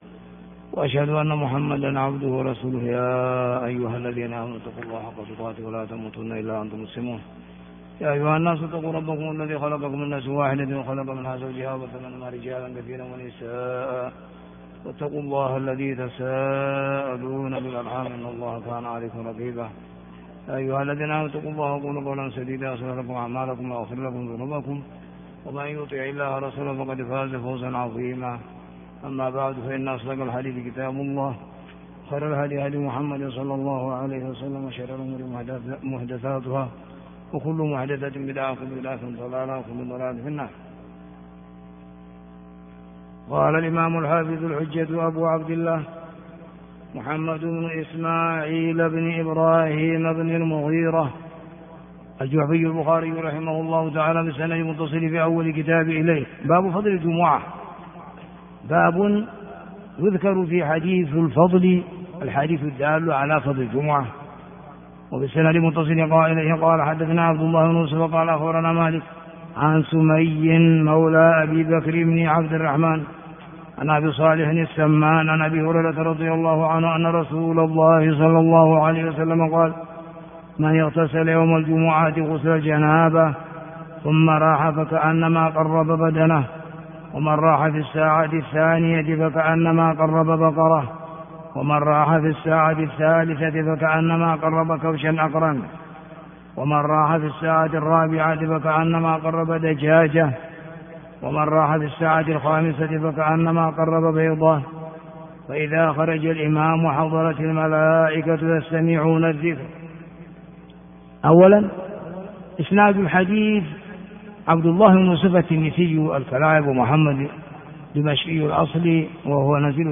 الدرس 073 - كتاب الجمعة - بَابُ فَضْلِ الجُمُعَةِ - ح 881